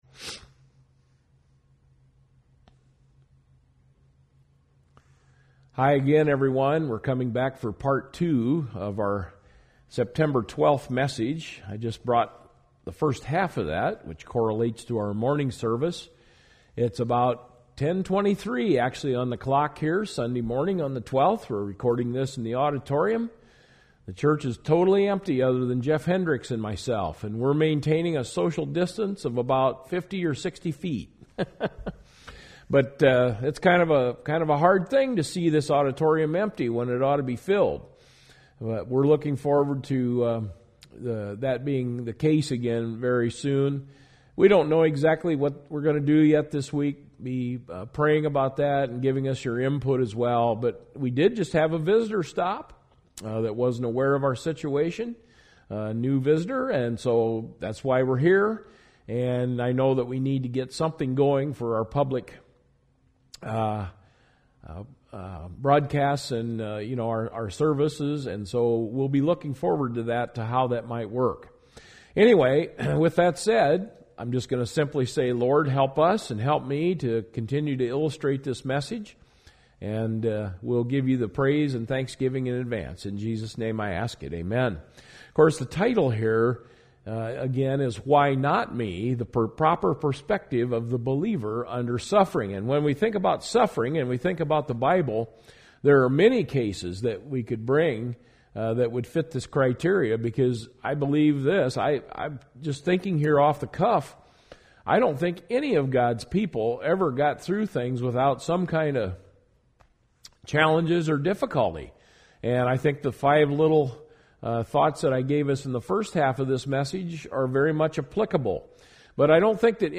Service Type: Sunday Evening Message « Why Not Me?